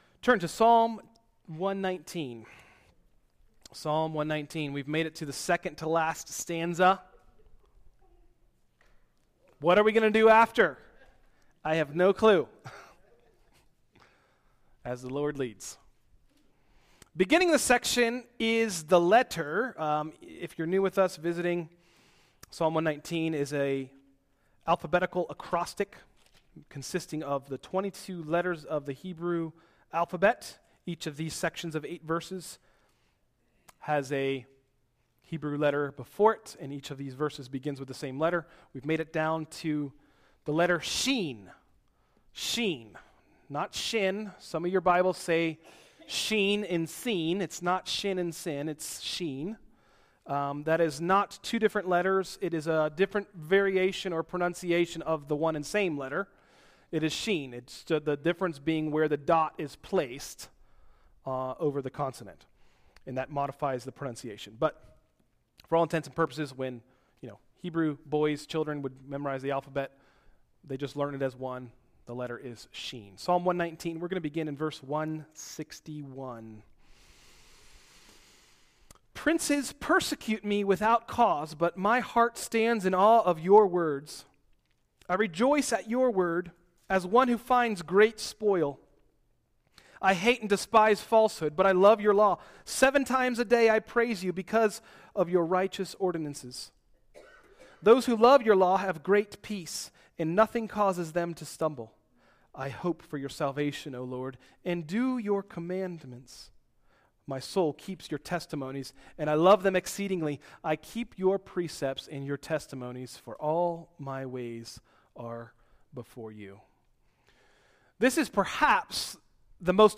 An verse-by-verse series on Psalm 119.